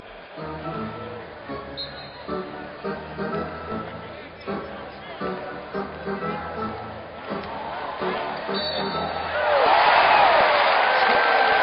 Hurray Sounds | Free Sound Effects | Sound Clips | Sound Bites